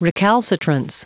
Prononciation du mot : recalcitrance